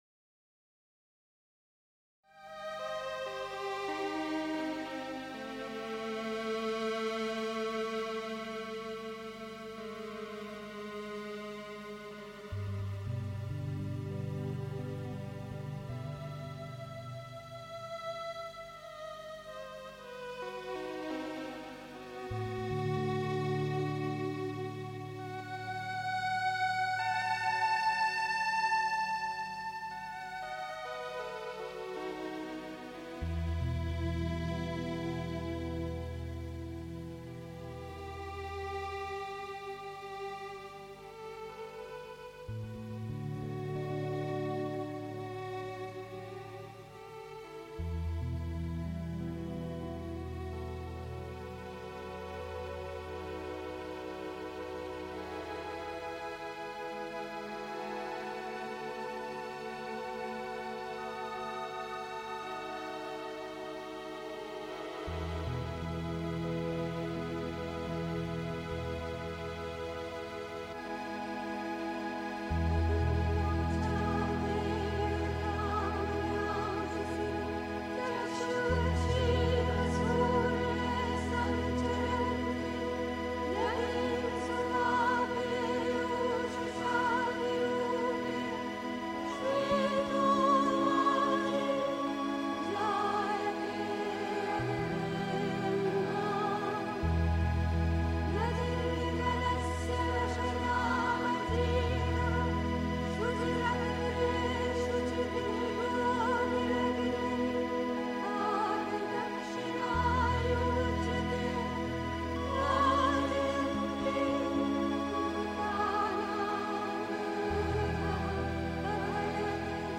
Februar 1958) 3. Zwölf Minuten Stille.